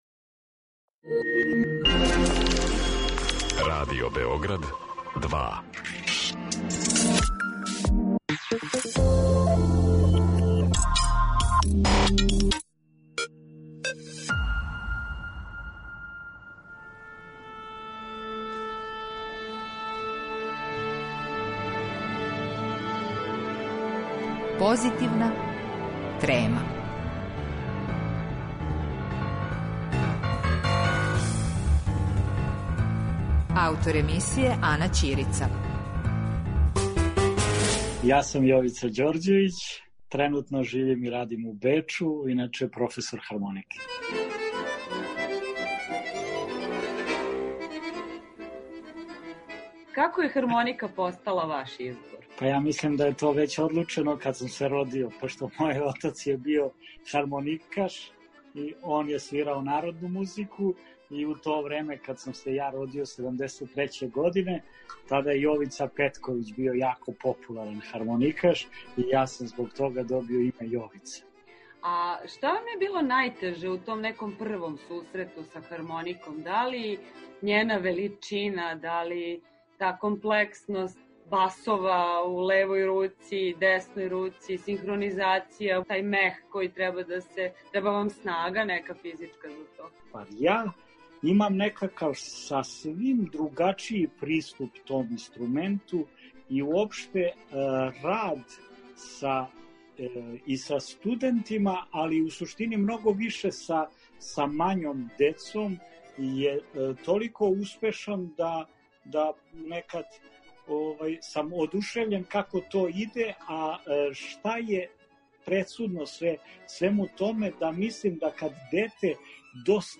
Разговор са хармоникашем